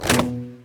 ArrowCrossBowShot-005.wav